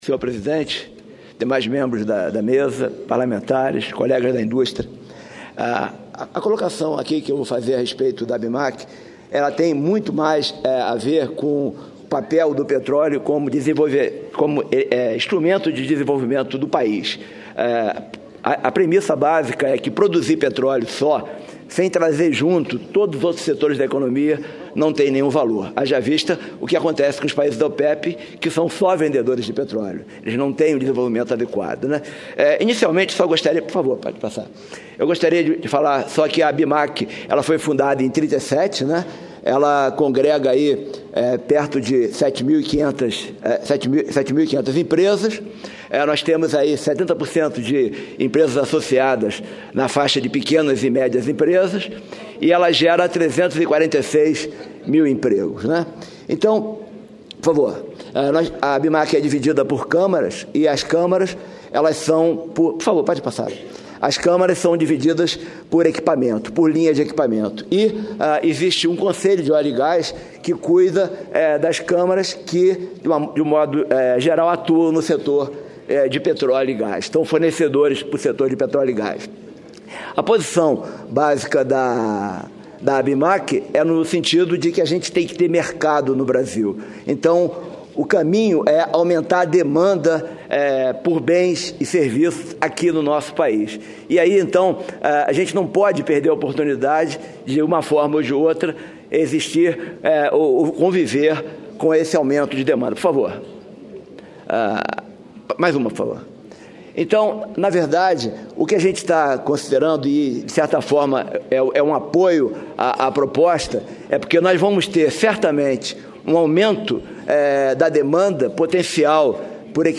Tópicos: Pronunciamento